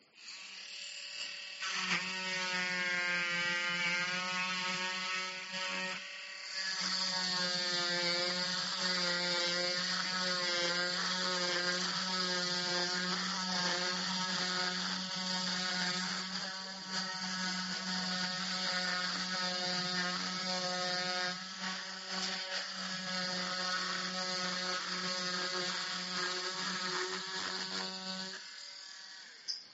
Play Scie à Plâtre - SoundBoardGuy
Play, download and share Scie à plâtre original sound button!!!!
scie-a-platre.mp3